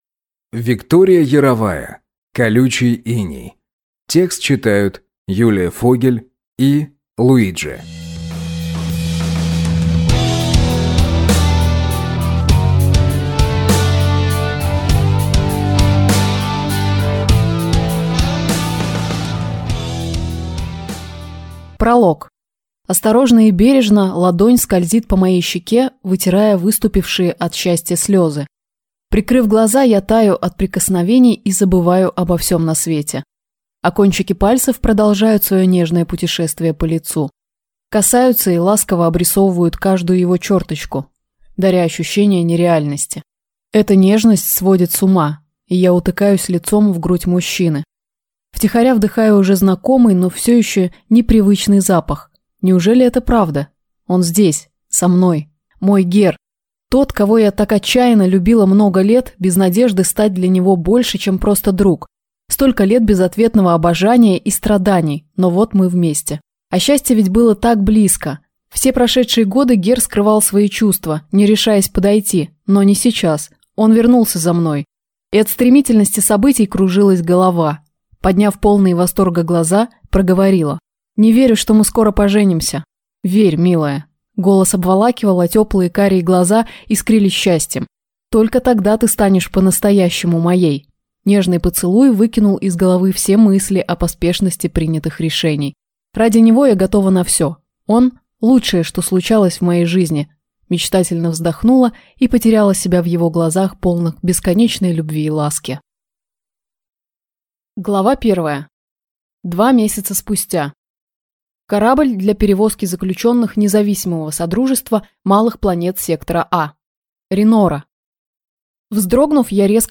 Аудиокнига Колючий иней | Библиотека аудиокниг